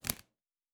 Cards Place 10.wav